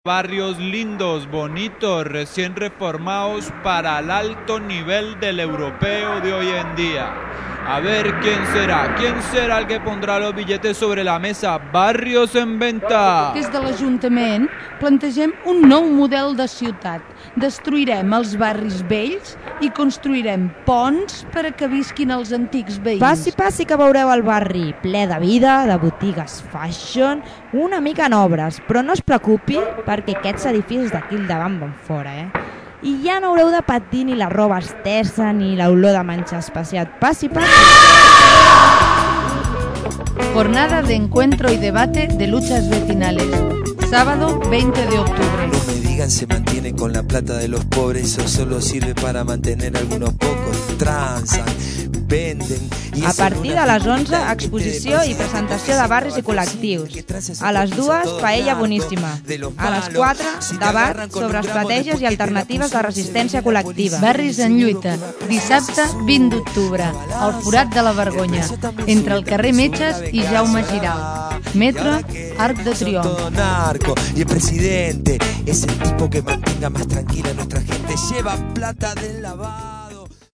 cuña audio - Barris en Lluita